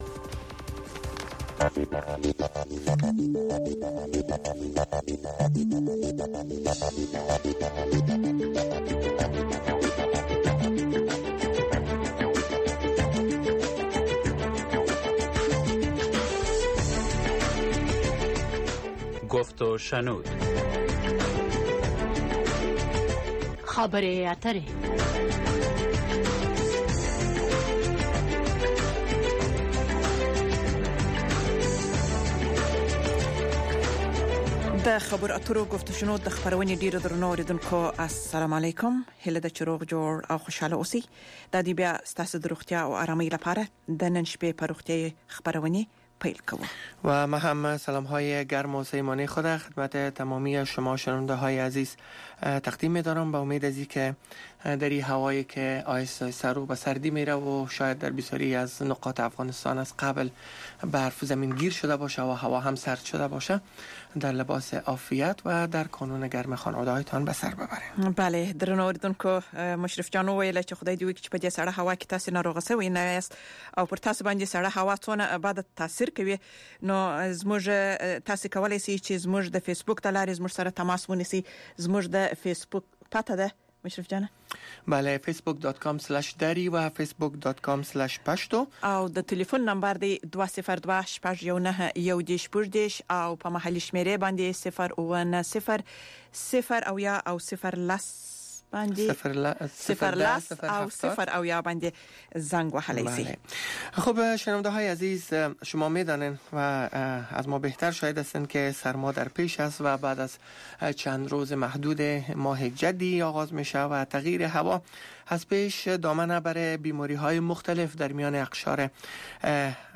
گفت و شنود - خبرې اترې، بحث رادیویی در ساعت ۰۸:۰۰ شب به وقت افغانستان به زبان های دری و پشتو است. در این برنامه، موضوعات مهم خبری هفته با حضور تحلیلگران و مقام های حکومت افغانستان به بحث گرفته می شود.